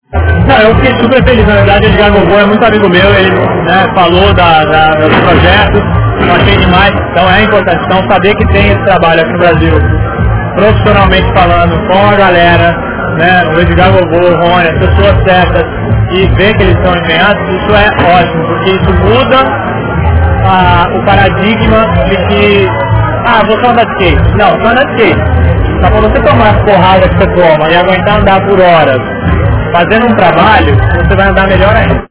entrevista-bob-burnquist